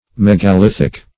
ancient monuments. -- Meg`a*lith"ic, a.